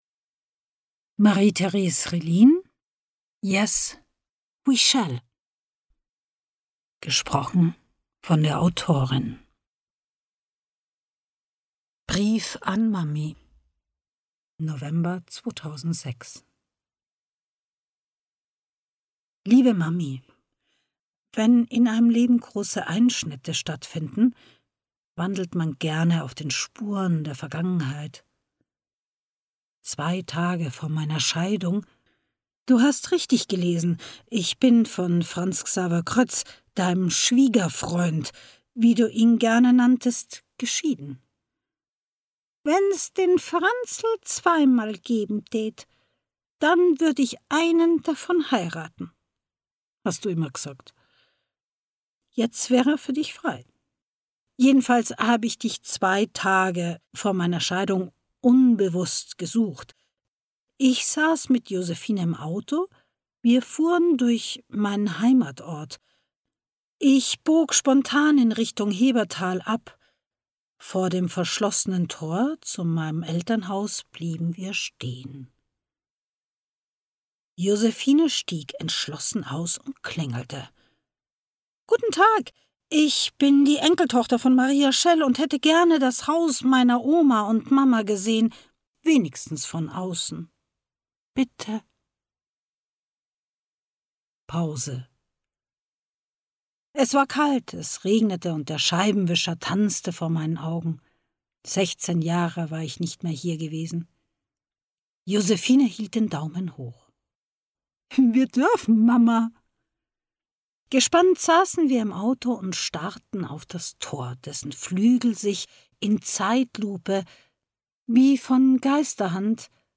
Yes, we schell! – Buch & Hörbuchprobe – Region18
von Marie Theres Relin (Autor, Sprecher)